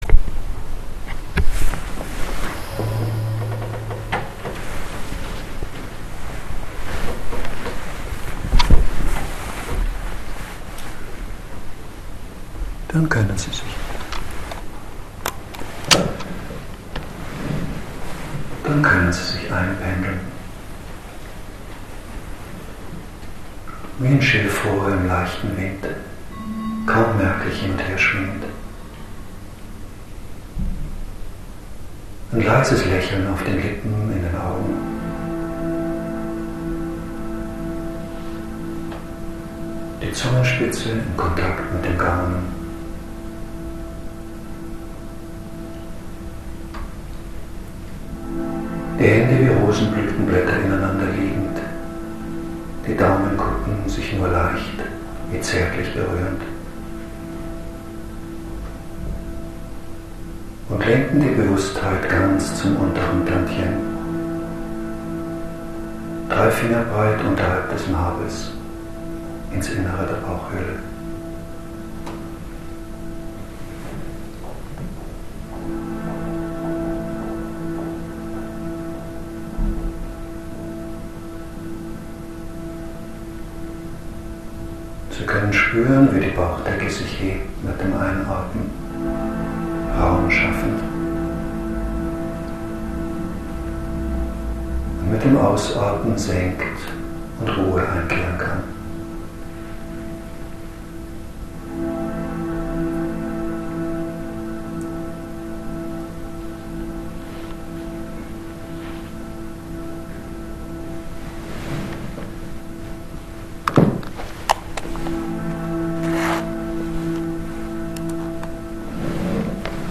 eine QiGong Übung zum Mitmachen